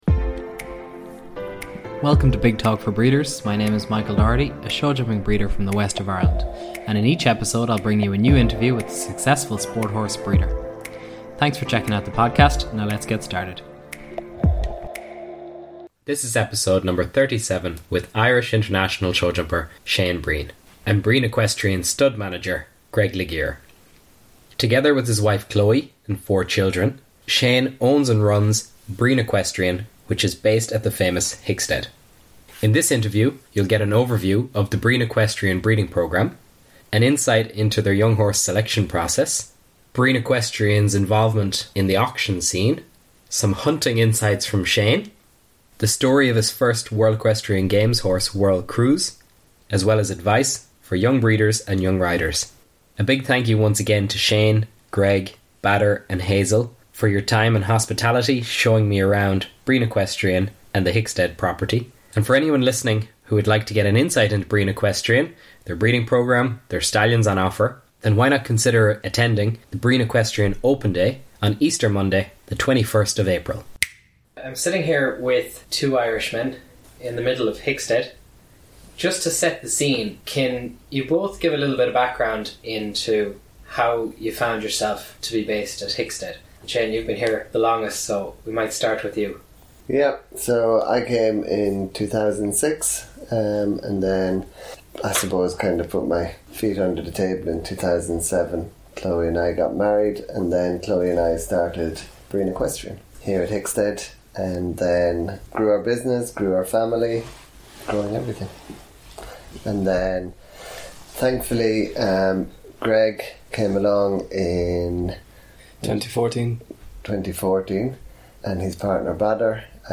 Play Rate Listened List Bookmark Get this podcast via API From The Podcast Big Talk For Breeders is a podcast series of interviews with internationally-successful sport horse breeders